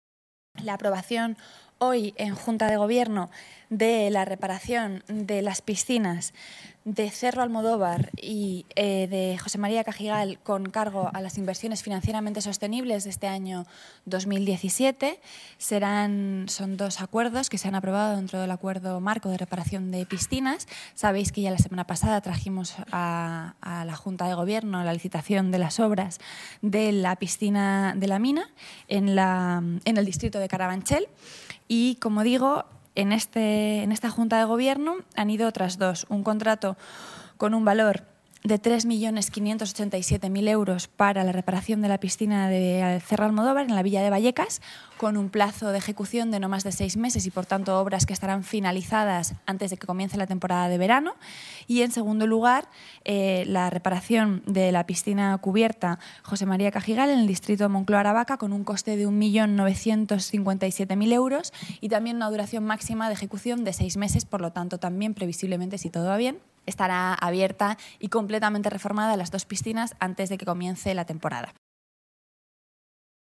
Nueva ventana:Rita Maestre habla sobre la aprobación en Junta de Gobierno de reparación de las dos piscinas